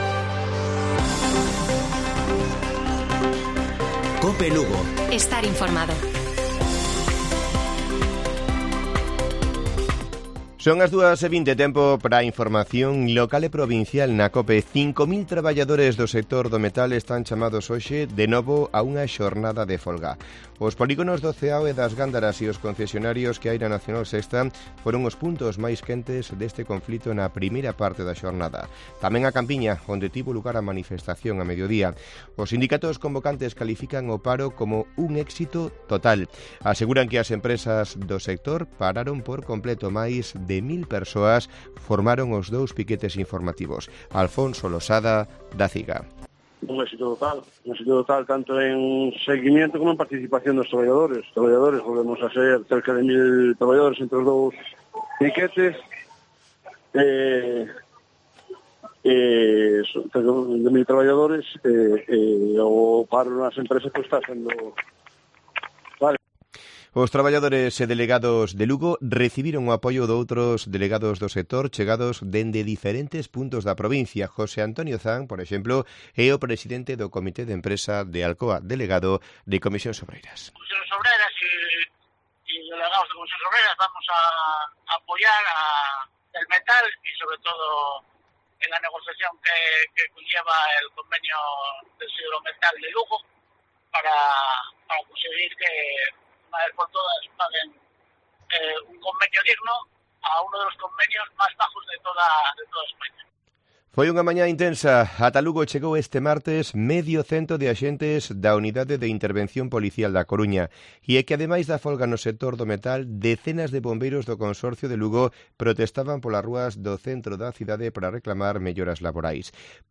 Informativo Mediodía de Cope Lugo. 23 DE MAYO. 14:20 horas